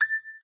ping_4.ogg